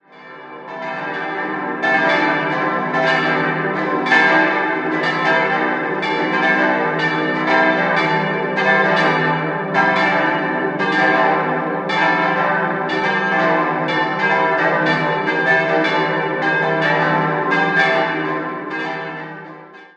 Eine eigenständige Pfarrei gibt es jedoch erst seit dem Jahr 1921. 5-stimmiges ausgefülltes Salve-Regina-Geläute: c'-d'-e'-g'-a' Die Glocken 1, 2 und 5 wurden 1901 von der Gießerei Spannagl in Regensburg gegossen, die Glocken 3 und 4 stammen aus der Gießerei Hamm (Frankenthal) aus dem Jahr 1949.